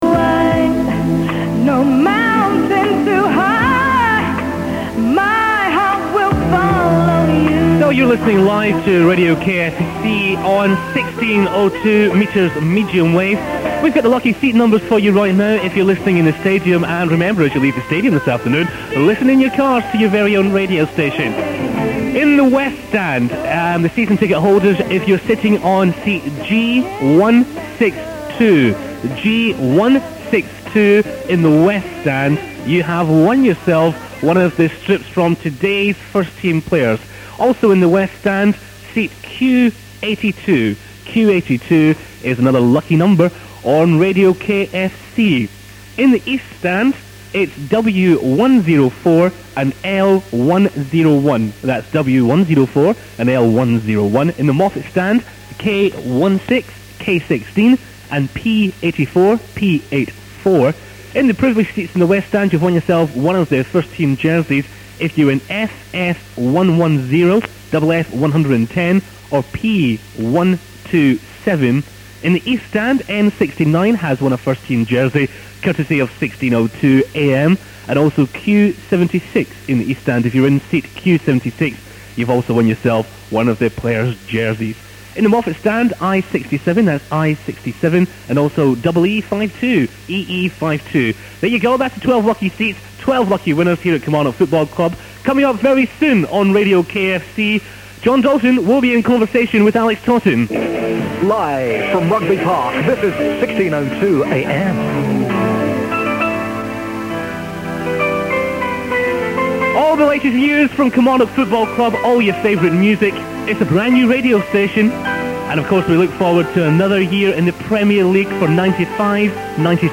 Anyway, listen to them ann as 1602 metres MW!!